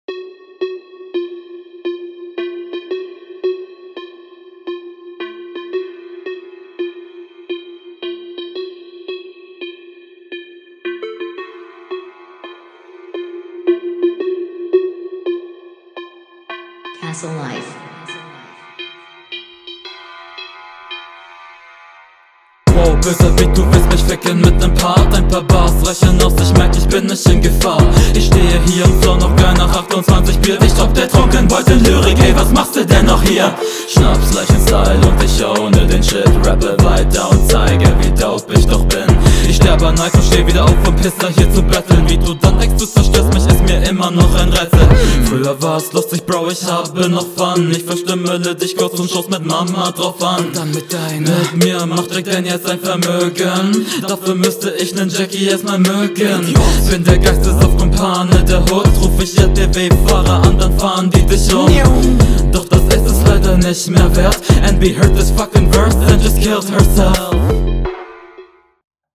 Lines sind hier besser aber die Sound Qualität ist Penis